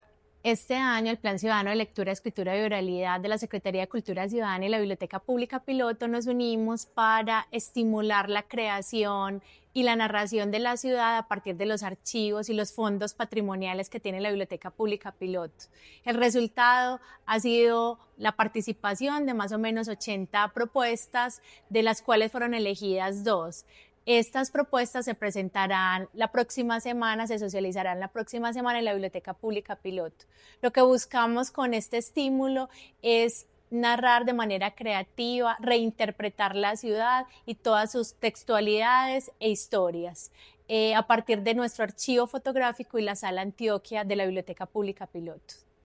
Audio de Declaraciones